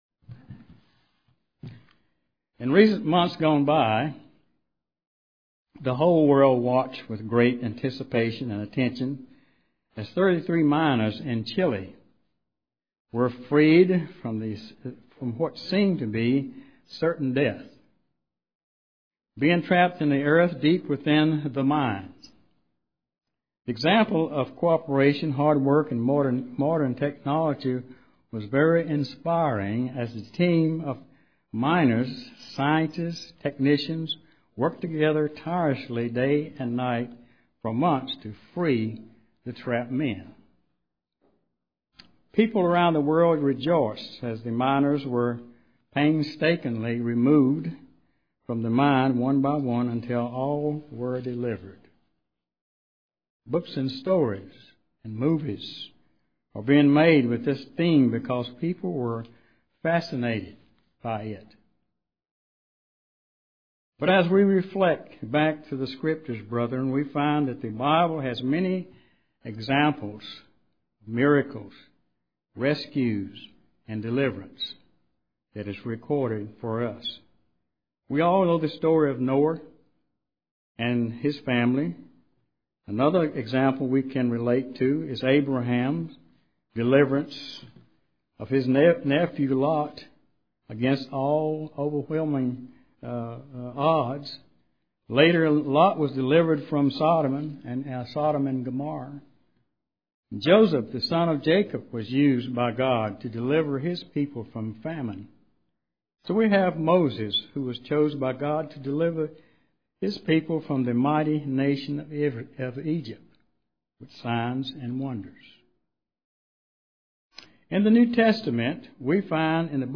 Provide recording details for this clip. Given in Charlotte, NC